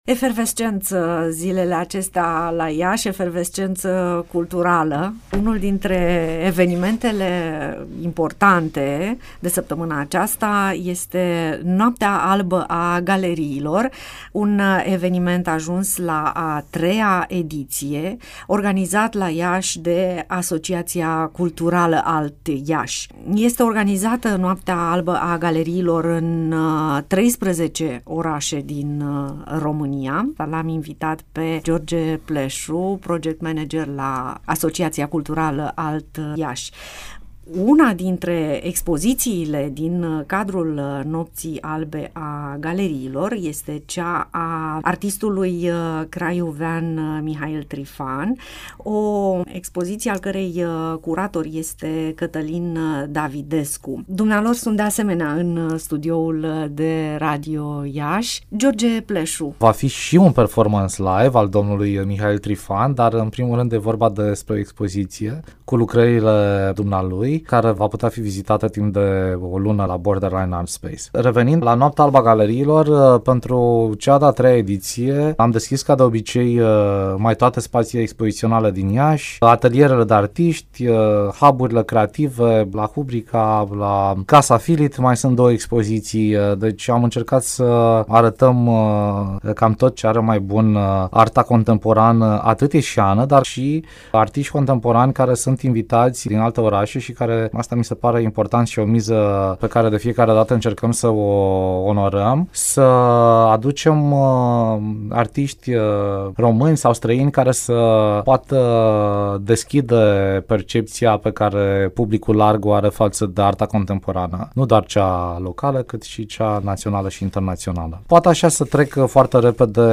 Noaptea Albă a Galeriilor, ediția 2018 – INTERVIU